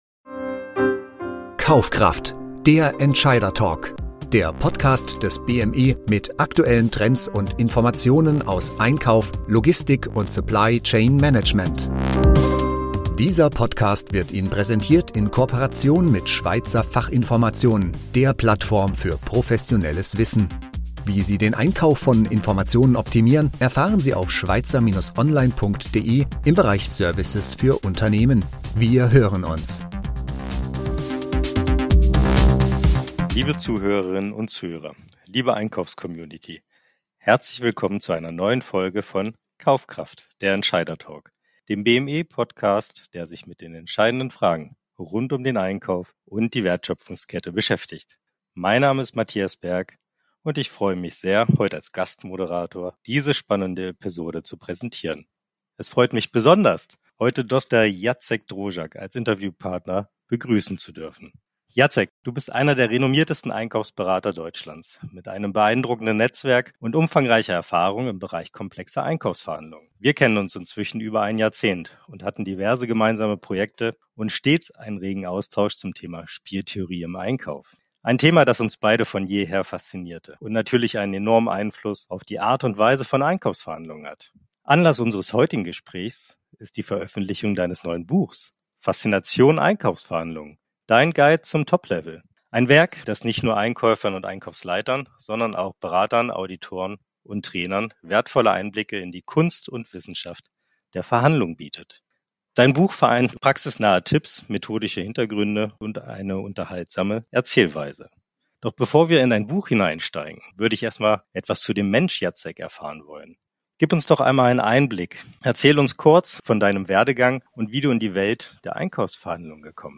In der aktuellen Folge von KaufKraft – Der Entscheidertalk erwartet Sie ein inspirierendes Gespräch